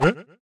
huh.wav